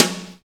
Index of /90_sSampleCDs/Roland L-CDX-01/SNR_Snares 4/SNR_Sn Modules 4
SNR WHACK 01.wav